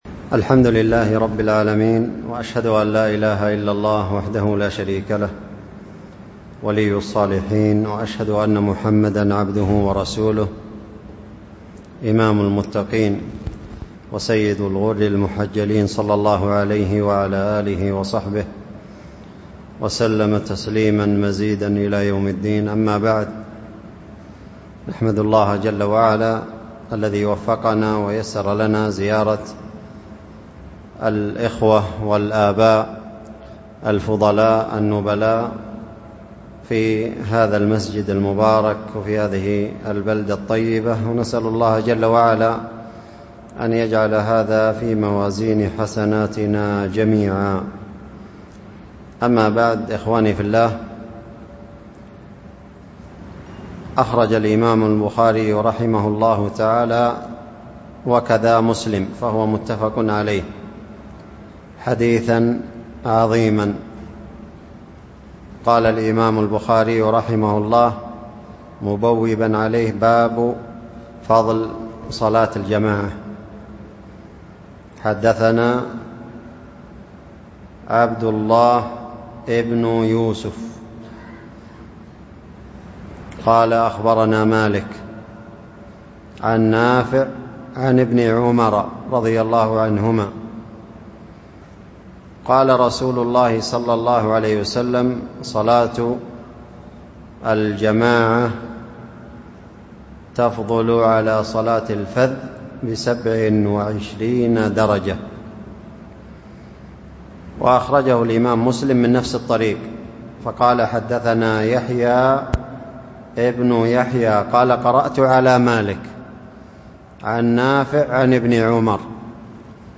كلمة بعد صلاة العصر، على حديث ابن عمر رضي الله عنهما : (صلاة الجماعة تفضل صلاة الفذ بسبع وعشرين درجة)، وهي ضمن سلسلة : نثر الدرر على ما في الصحيحين من ترجمة مالك عن نافع عن ابن عمر .